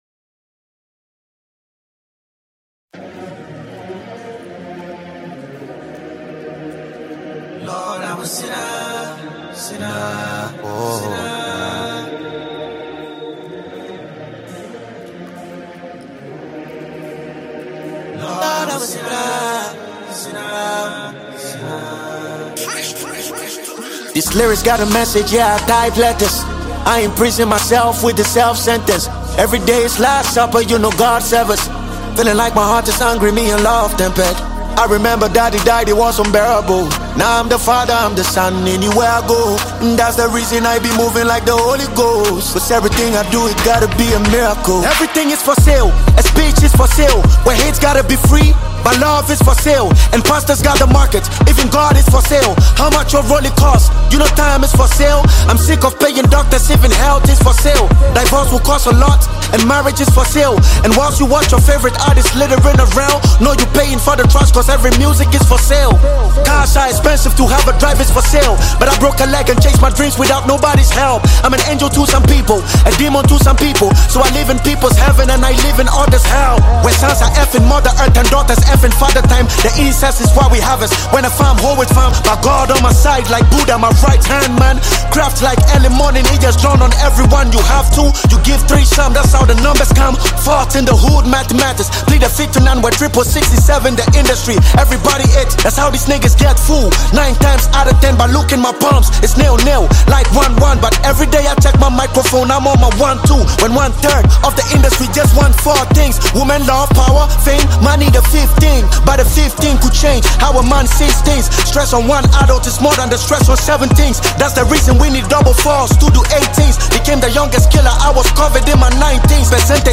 Award-winning Ghanaian rapper